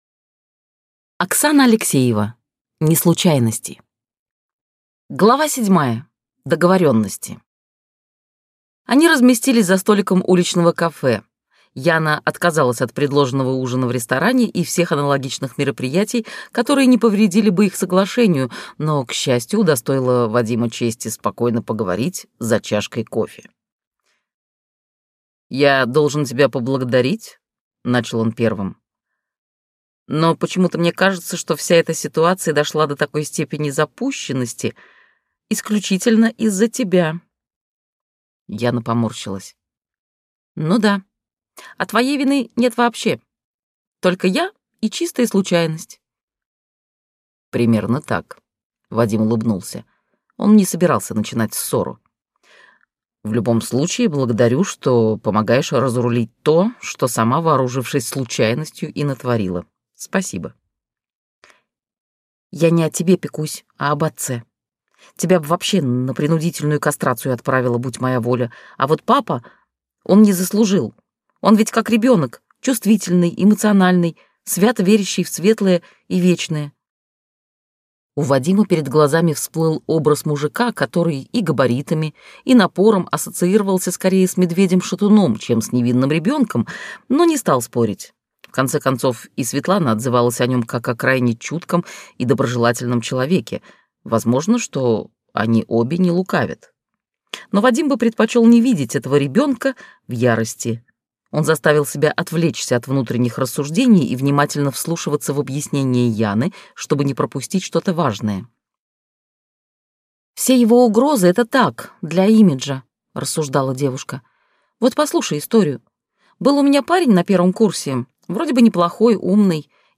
Аудиокнига Неслучайности | Библиотека аудиокниг
Прослушать и бесплатно скачать фрагмент аудиокниги